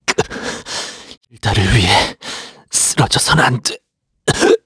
Siegfried-Vox_Dead_kr.wav